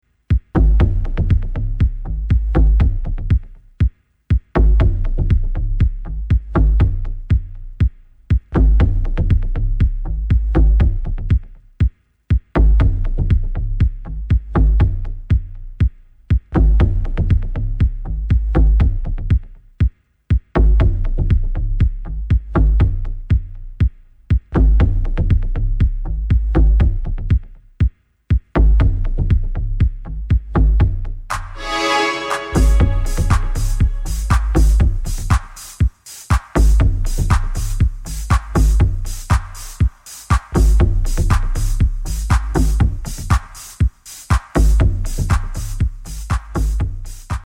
supplier of essential dance music
Massive. Sub. Killer. Will levitate your sound system.
House